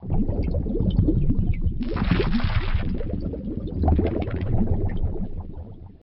sea.wav